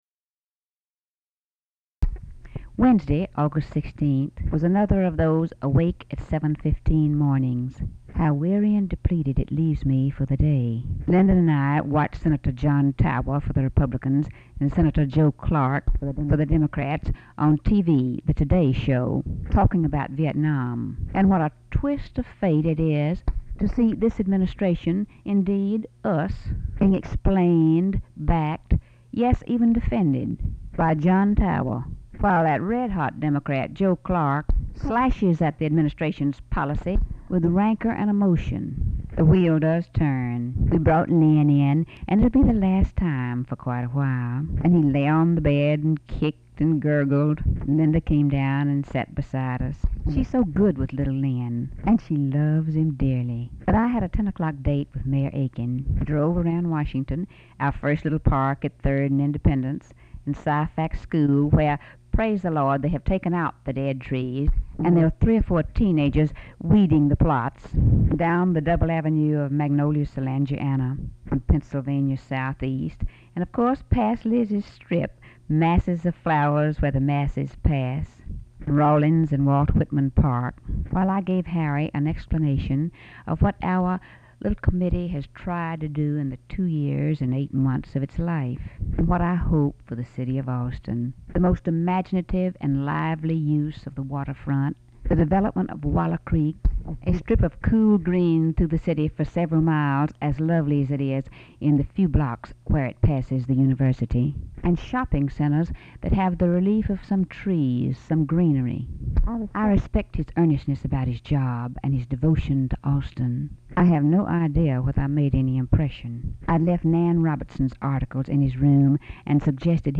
Audio diary and annotated transcript, Lady Bird Johnson, 8/16/1967 (Wednesday) · Discover Production